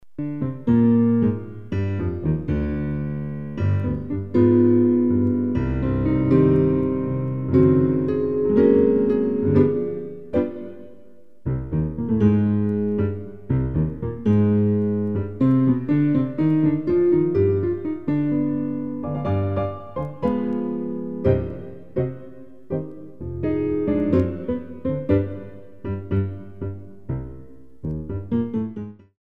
45 selections (67 minutes) of Original Piano Music
tango